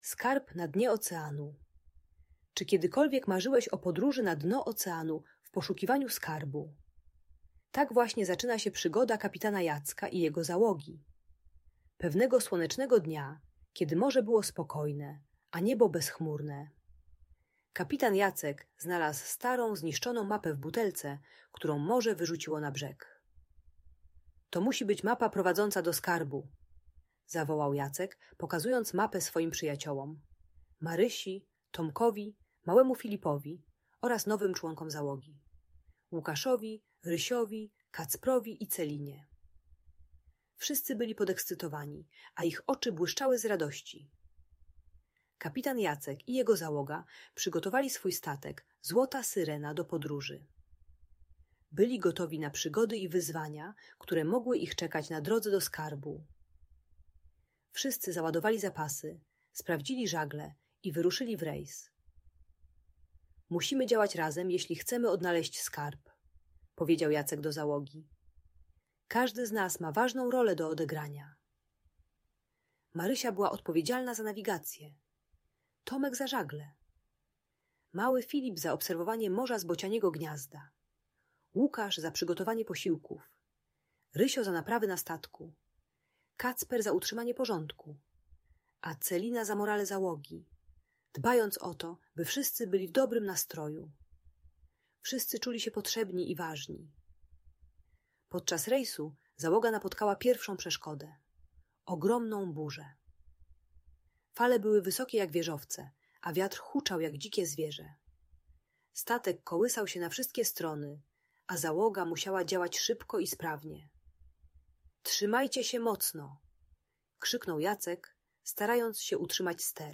Przygoda na dnie oceanu - Audiobajka